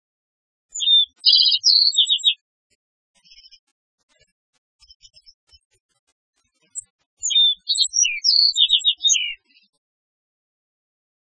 鳥の鳴声その１
〔アオジ〕チチッ／チュッチンチュルリーティーリューリー（さえずり）／山地の疎林